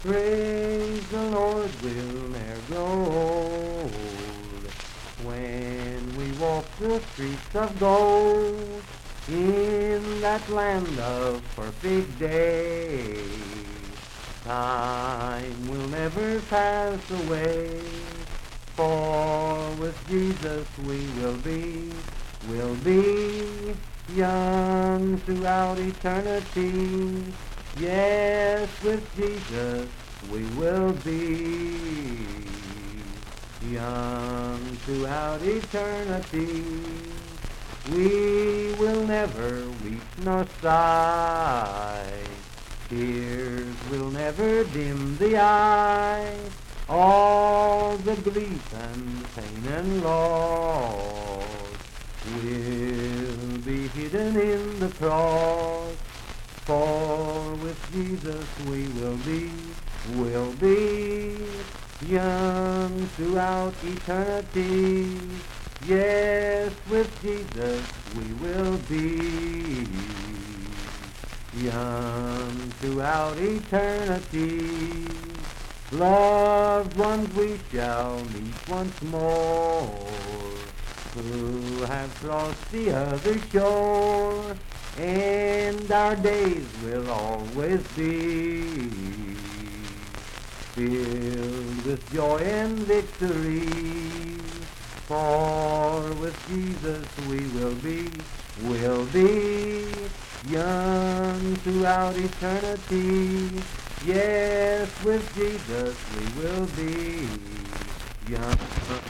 Unaccompanied vocal music
Hymns and Spiritual Music
Voice (sung)
Parkersburg (W. Va.), Wood County (W. Va.)